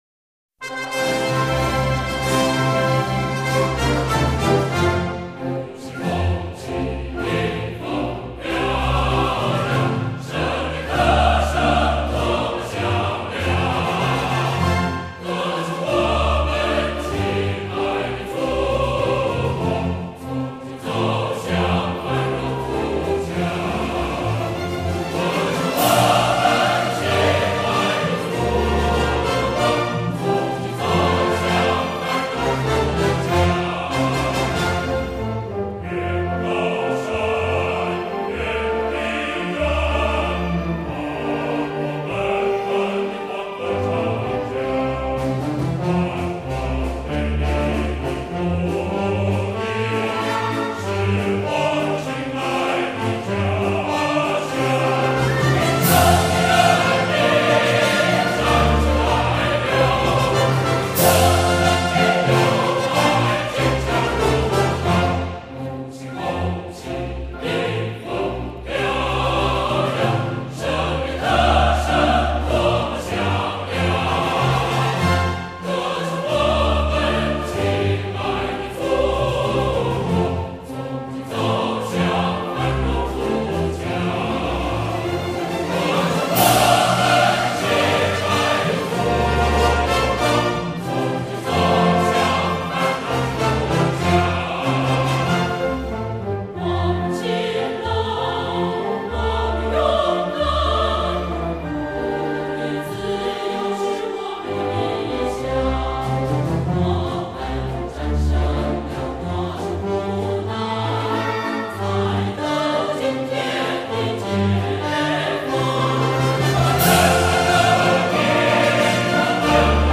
录音制式：DDD STEREO
唱片除了收录有合唱版、军乐队版以及管弦
但各个频段的细节表现以及整体平衡感已经足够令人满意。